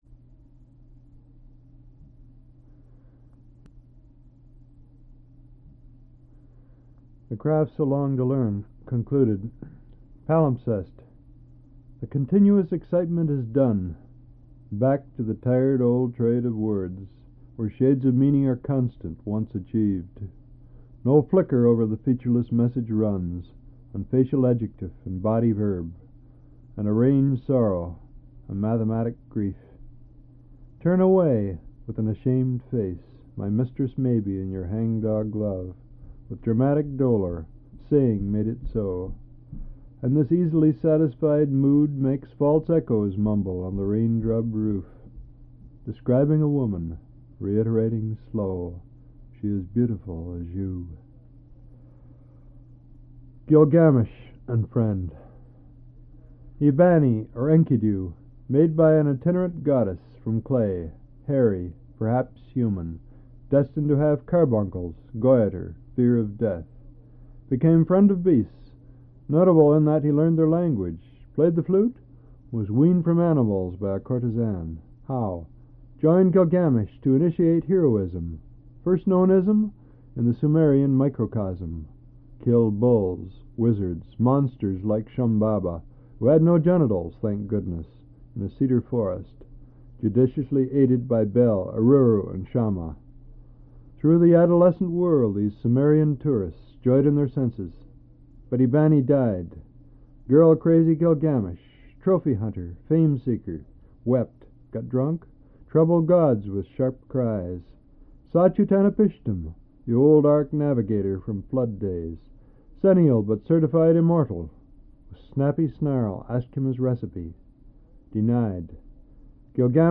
Al Purdy reads his poetry
1/4"' Reel-to-Reel Tape